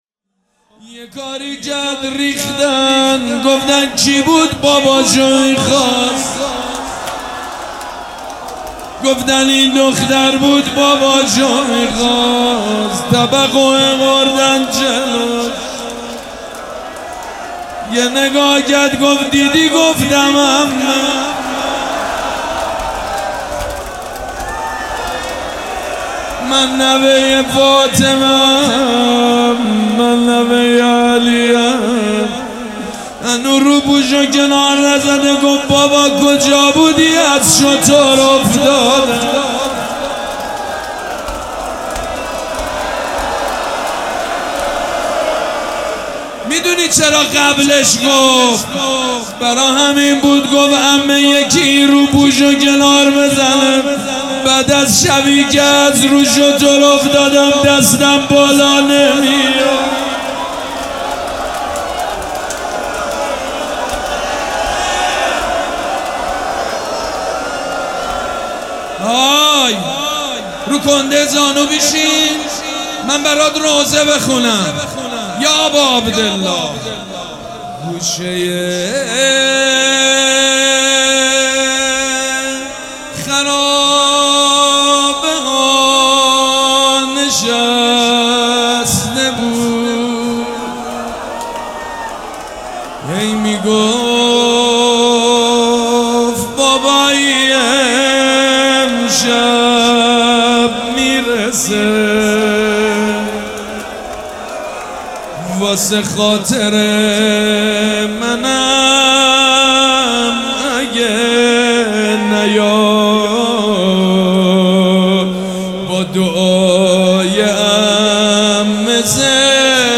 روضه بخش دوم
حاج سید مجید بنی فاطمه پنجشنبه 11 مهر 1398 هیئت ریحانه الحسین سلام الله علیها
سبک اثــر روضه مداح حاج سید مجید بنی فاطمه